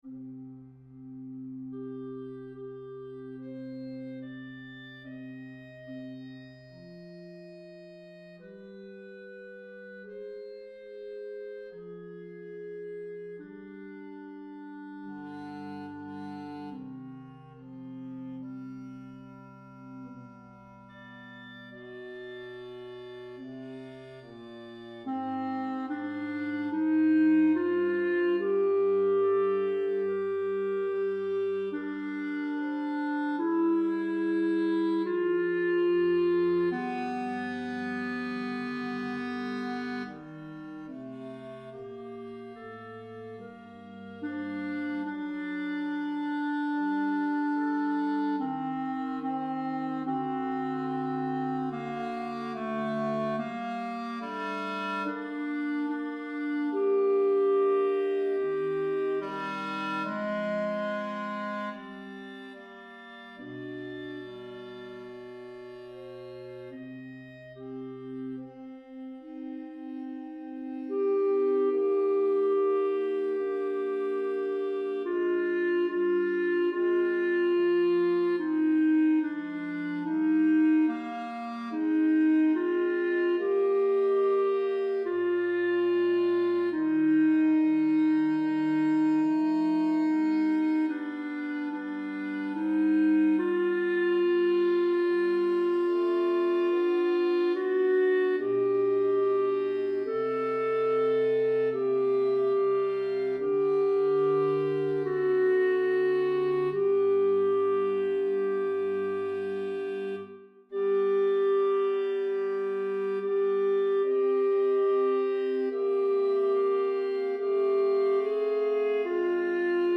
In_the_midst_of_life_RR_Alto.mp3